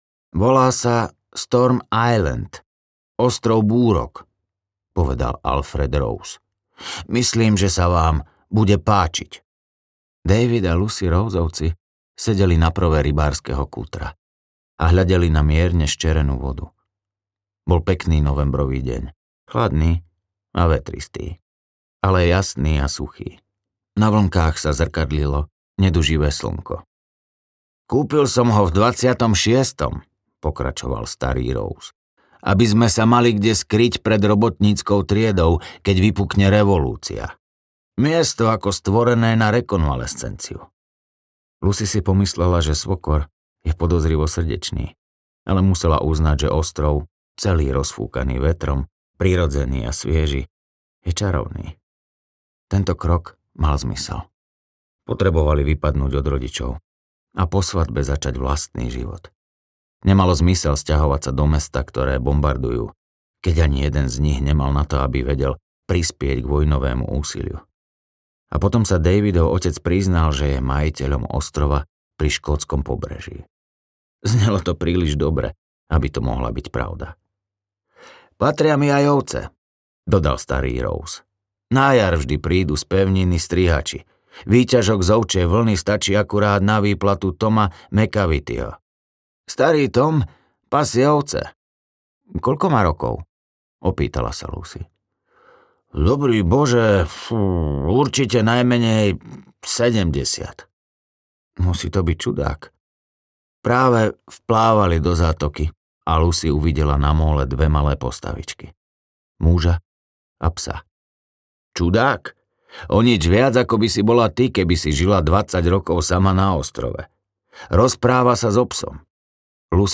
Audio knihaOko ihly
Ukázka z knihy